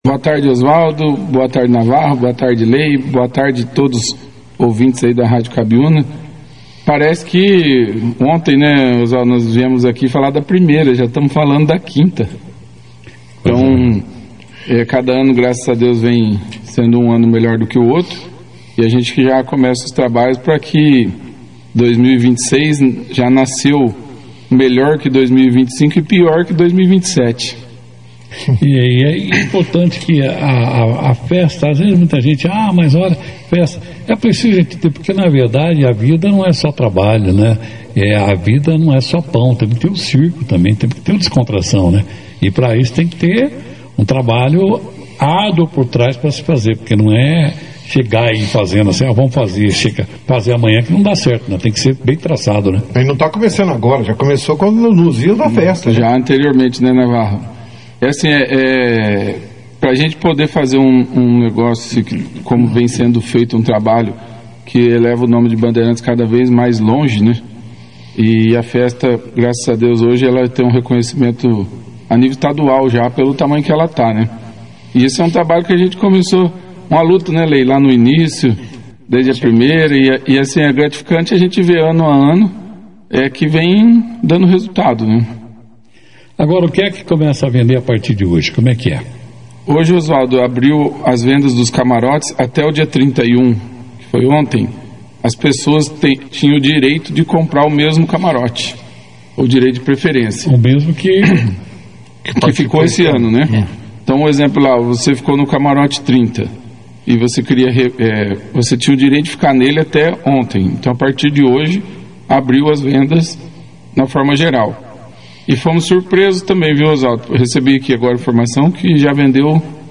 Durante a entrevista, os representantes da Abarex também compartilharam curiosidades sobre artistas que já passaram pela ExpoBan, além de comentar sobre melhorias na infraestrutura e expectativas de público para a próxima edição do evento.